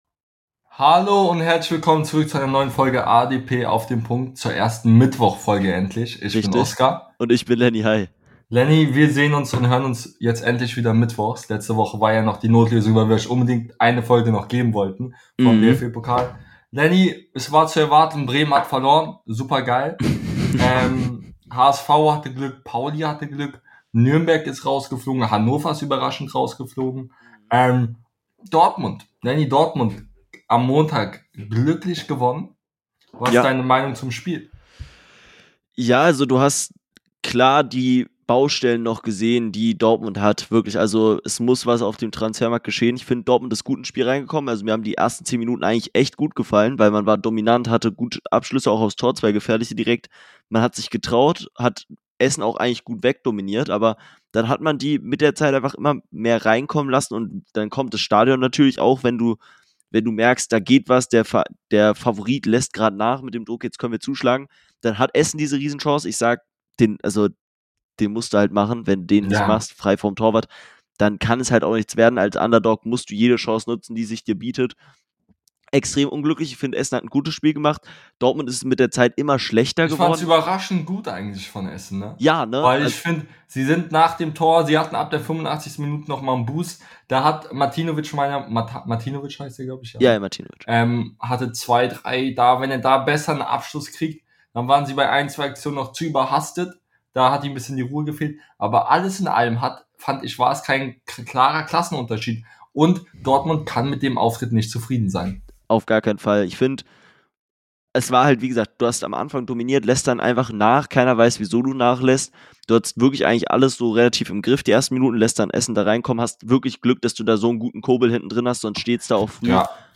In der heutigen Folge reden die beiden Hosts über den Bundesliga Start , tippen die Abschlusstabelle , geben sehr wilde hottakes und vieles mehr